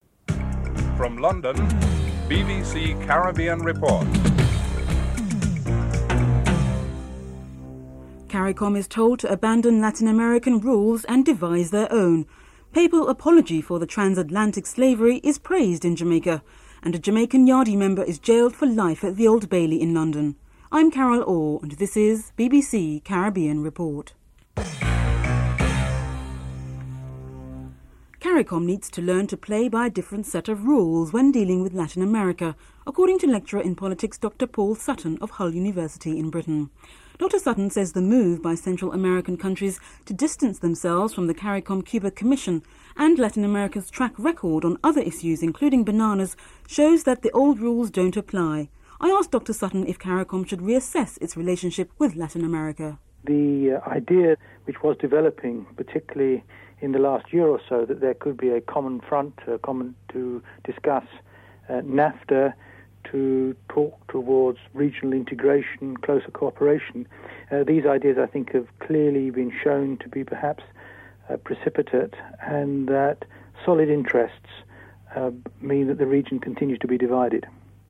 1. Headlines (00:00-00:35)
Interview with Rex Nettleford, Professor, The University of the West Indies (6:11-09:47)
Interview with Bernie Grant, MP, Labour Party (13:53-14:50)